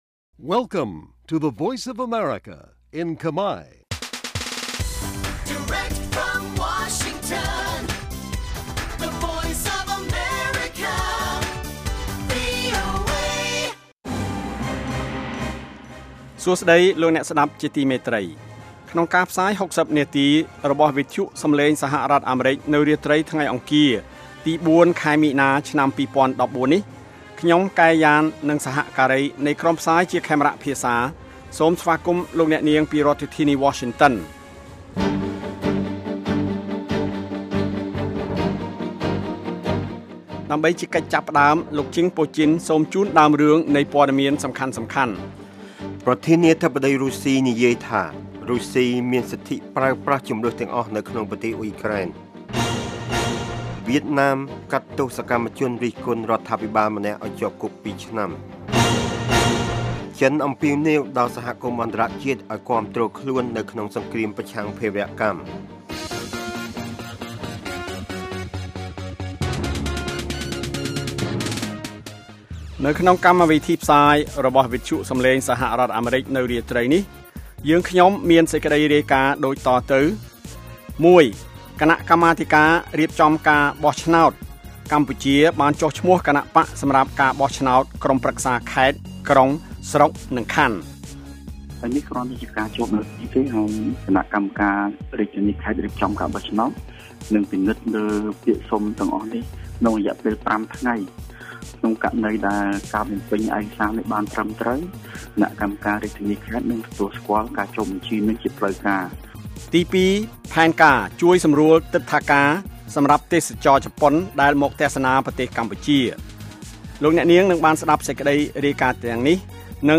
នេះជាកម្មវិធីផ្សាយប្រចាំថ្ងៃតាមវិទ្យុ ជាភាសាខ្មែរ រយៈពេល ៦០ នាទី ដែលផ្តល់ព័ត៌មានអំពីប្រទេសកម្ពុជានិងពិភពលោក ក៏ដូចជាព័ត៌មានពិពណ៌នា ព័ត៌មានអត្ថាធិប្បាយ កម្មវិធីតន្ត្រី កម្មវិធីសំណួរនិងចម្លើយ កម្មវិធីហៅចូលតាមទូរស័ព្ទ និង បទវិចារណកថា ជូនដល់អ្នកស្តាប់ភាសាខ្មែរនៅទូទាំងប្រទេសកម្ពុជា។ កាលវិភាគ៖ ប្រចាំថ្ងៃ ម៉ោងផ្សាយនៅកម្ពុជា៖ ៨:៣០ យប់ ម៉ោងសកល៖ ១៣:០០ រយៈពេល៖ ៦០នាទី ស្តាប់៖ សំឡេងជា MP3